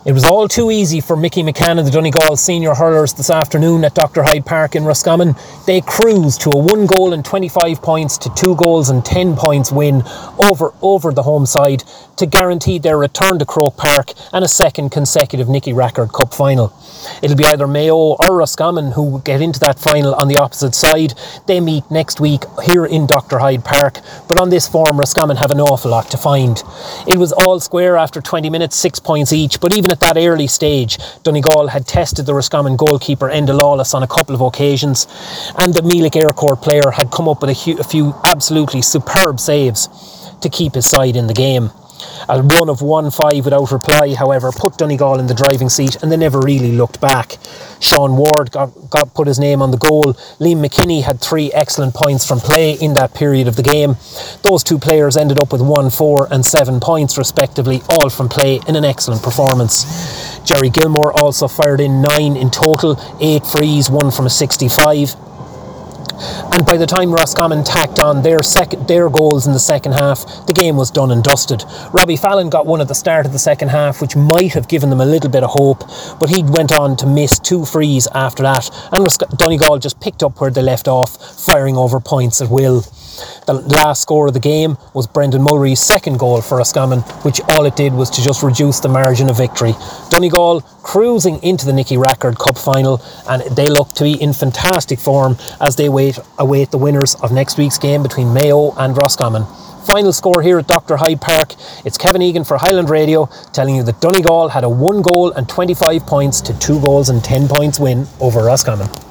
Hurling-FT.wav